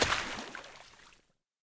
shallow-water-01.ogg